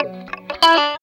28 GUIT 3 -R.wav